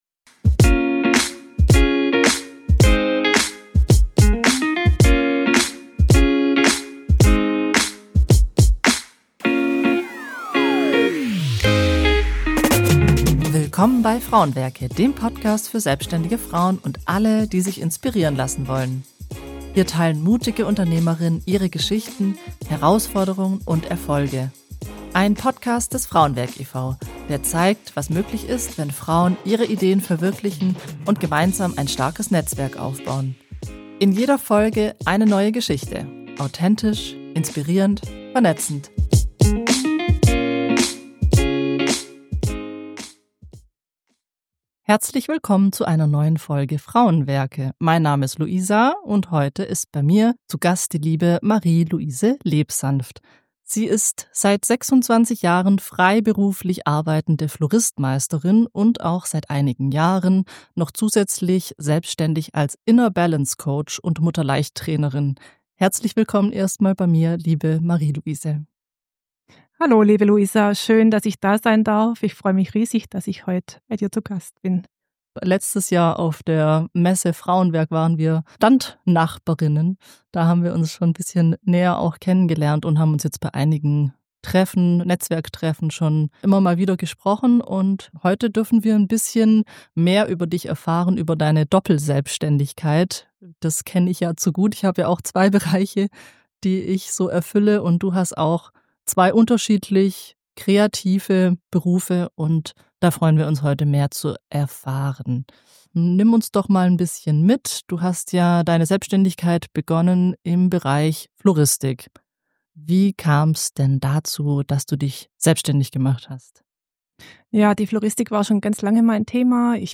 Ein Gespräch über Mut zur Veränderung, Lebensbalance und die Kraft, Herzensberufe miteinander zu verbinden.